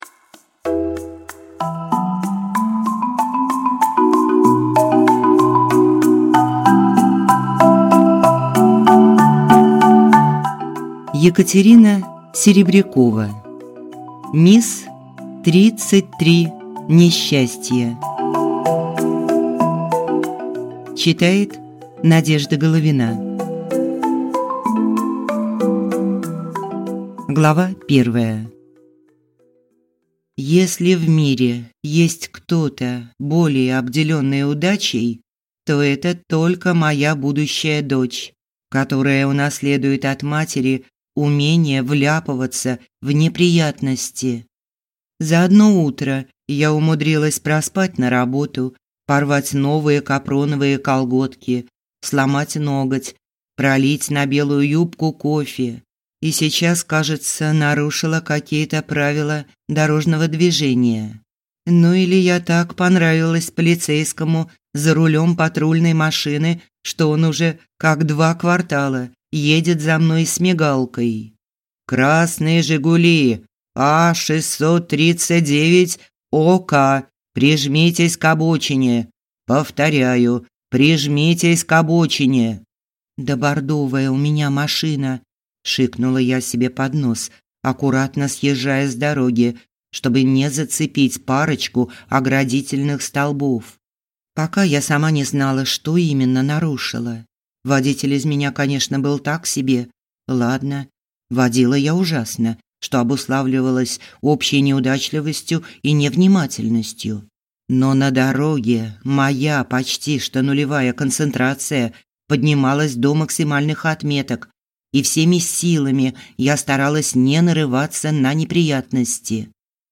Aудиокнига Мисс тридцать три несчастья